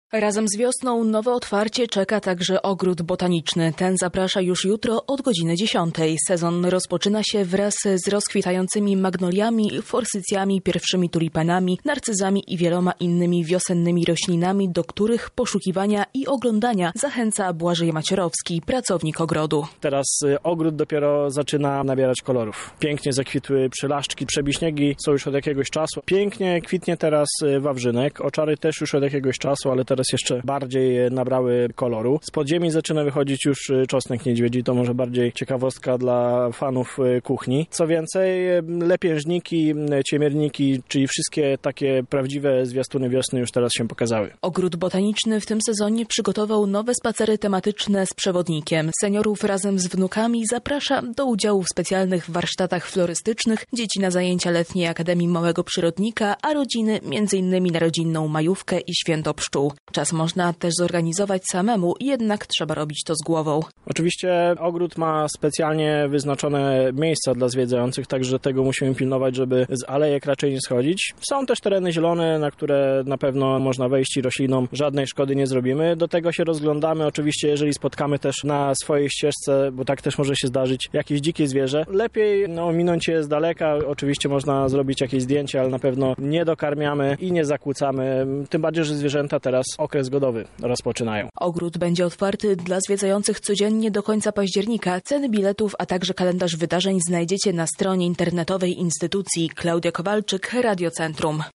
Szczegóły zna nasza reporterka: